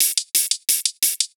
Index of /musicradar/ultimate-hihat-samples/175bpm
UHH_ElectroHatB_175-03.wav